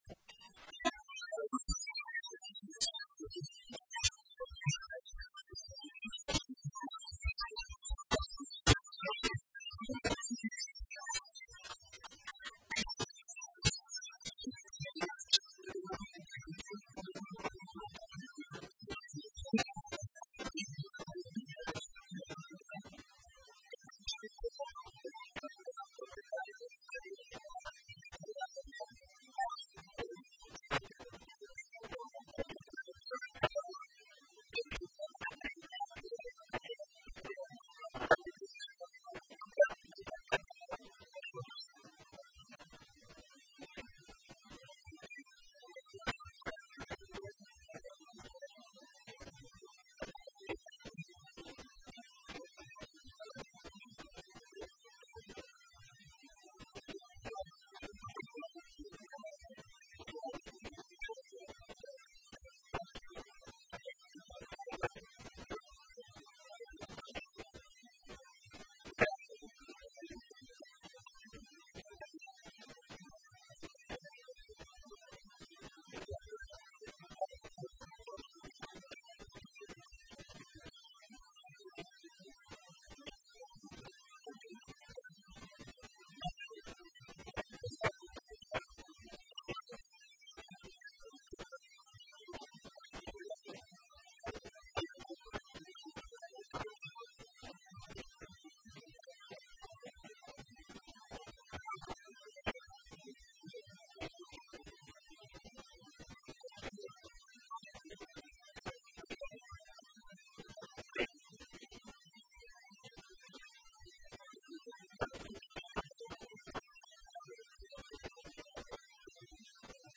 Conferencia de Prensa